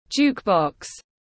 Máy hát tự động tiếng anh gọi là jukebox, phiên âm tiếng anh đọc là /ˈdʒuːk.bɒks/